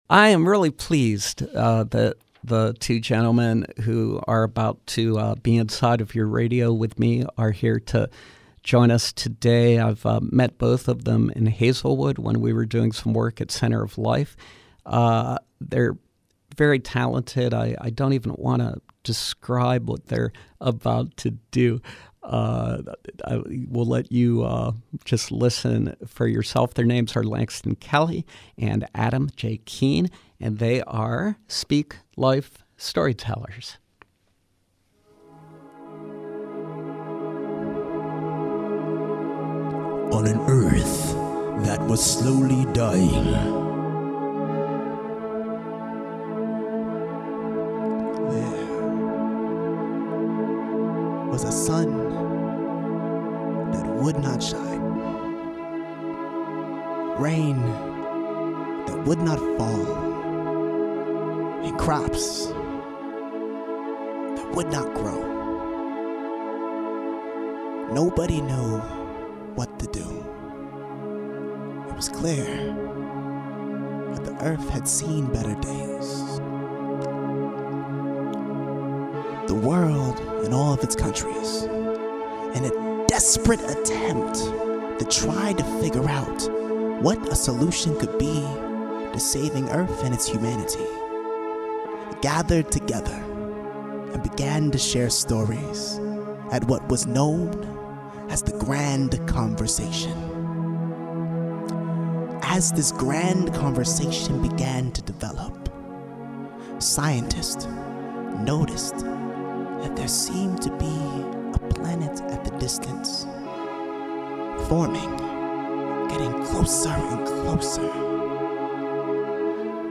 Live Performance: Speak Life Storytellers